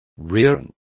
Complete with pronunciation of the translation of rerunning.